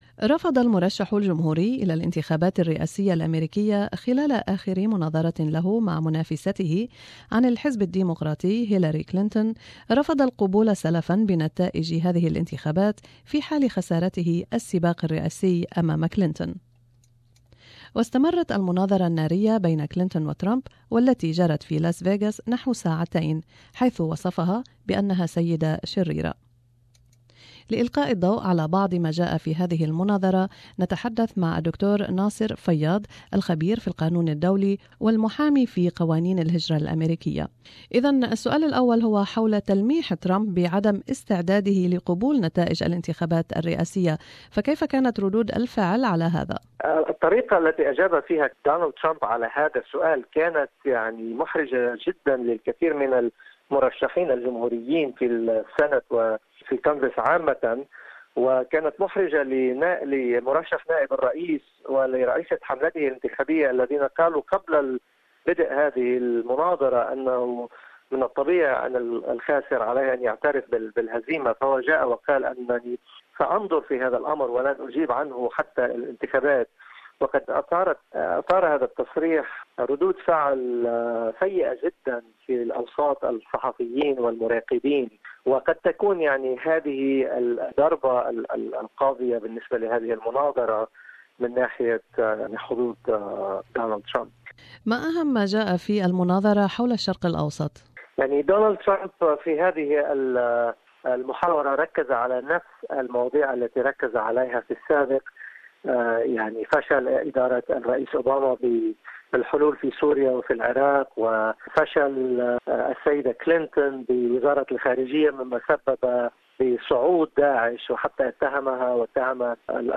Republican presidential candidate Donald Trump has again refused to commit to accepting the result of November's presidential election. In the final television debate against Democrat nominee Hillary Clinton, Mr Trump would not make the pledge when pressed by the moderator. Interview